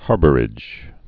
(härbər-ĭj)